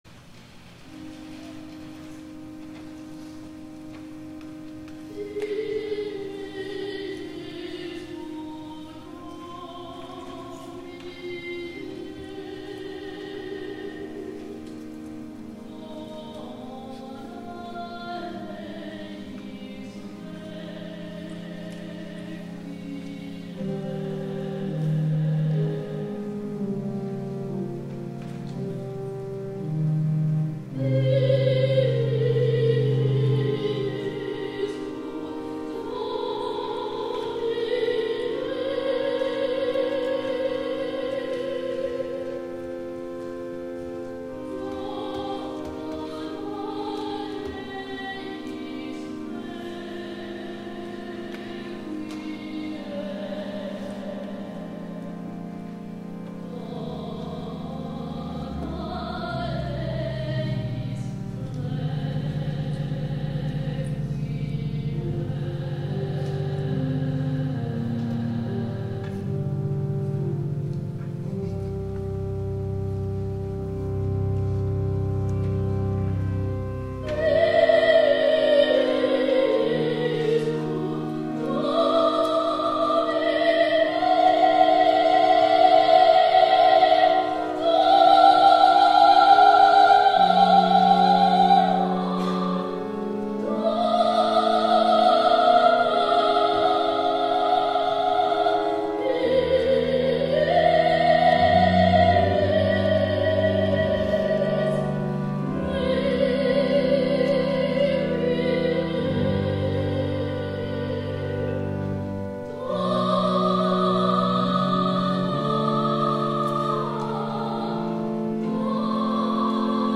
THE SUNG RESPONSE